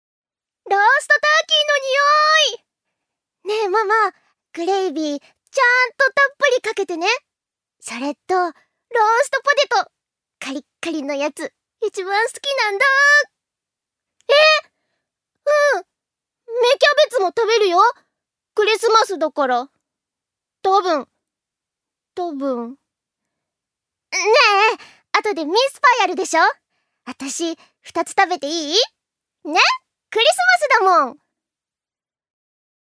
預かり：女性
2. セリフ２